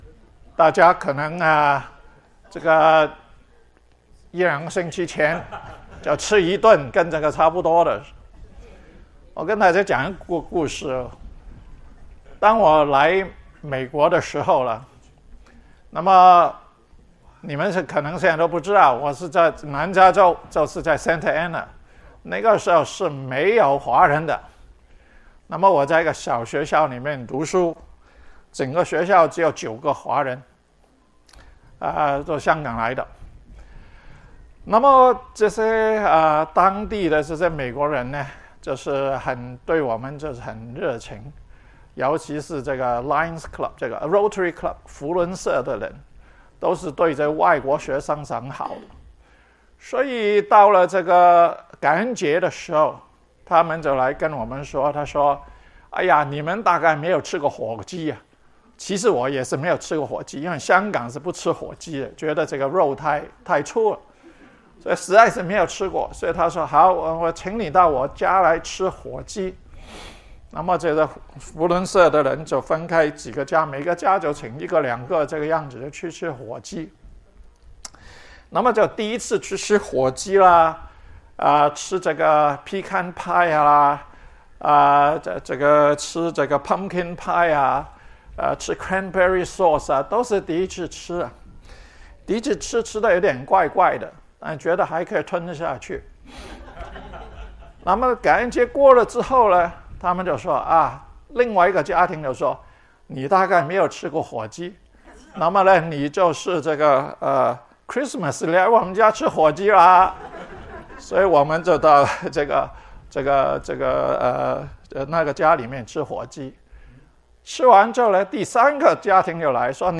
活在神的设计里: 細讀以弗所書 – 第十八講